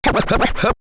Scratch 28.wav